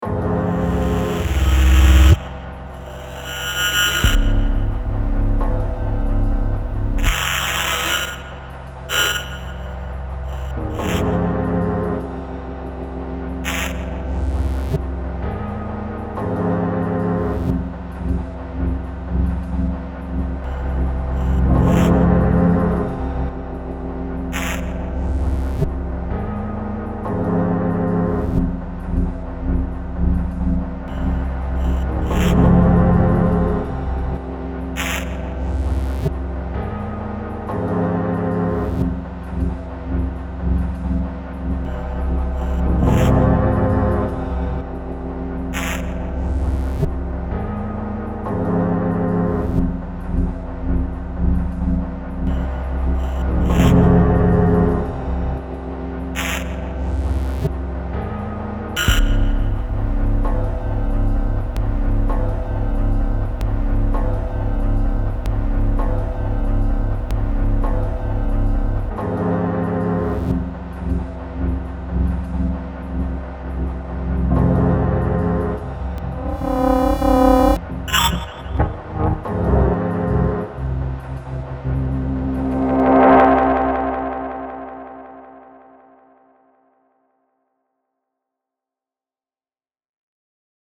Weird distorted tension.